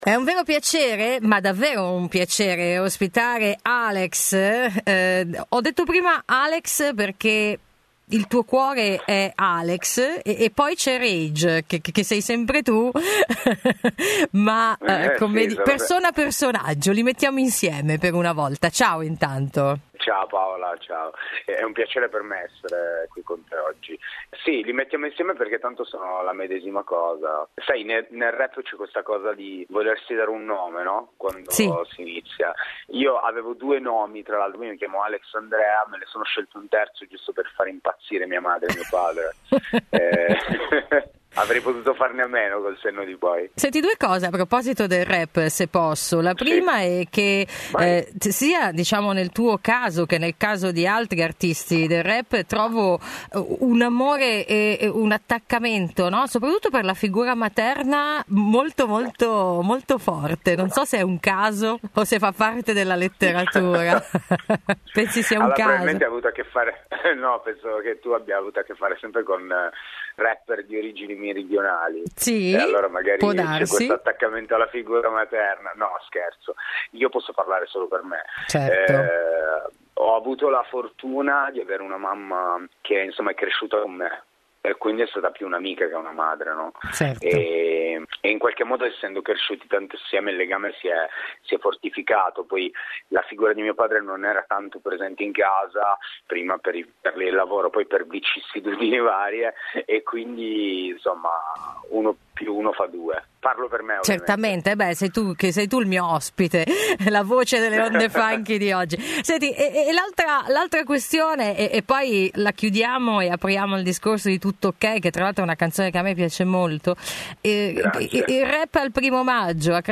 Interviste
Un ritorno al rap che è ben diverso dalla trap, intervista esclusiva